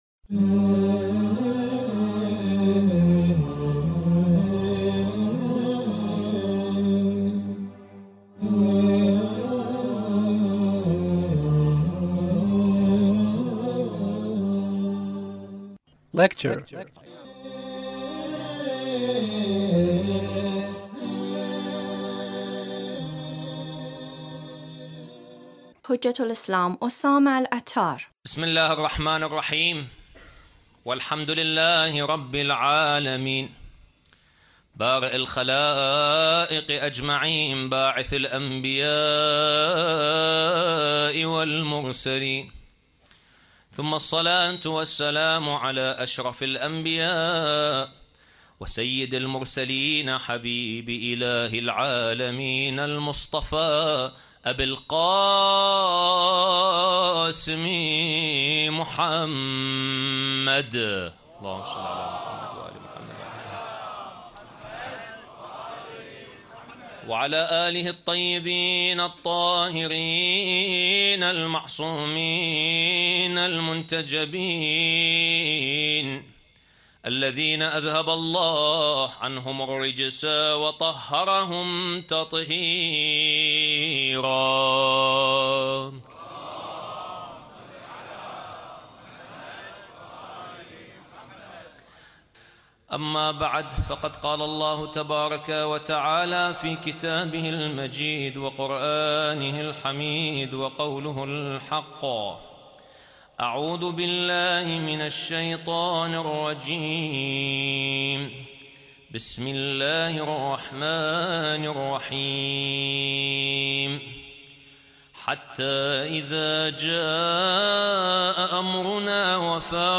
Lecture (26)